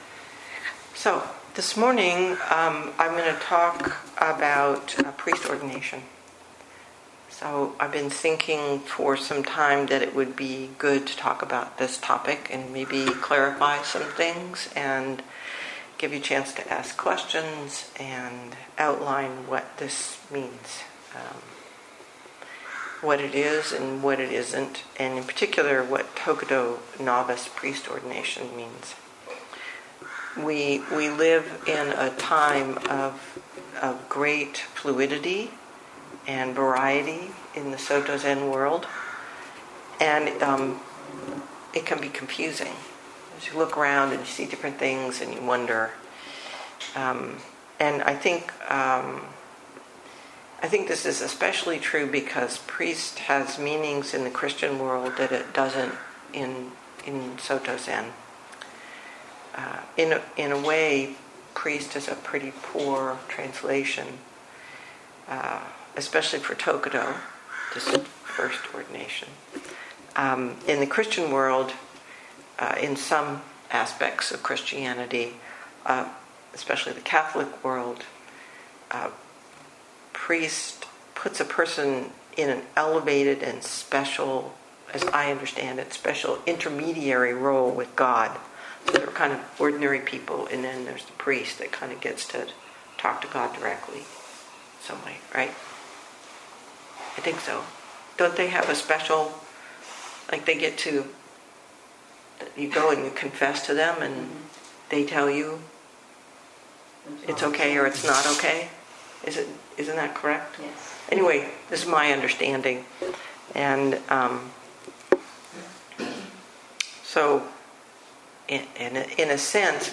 2022 in Dharma Talks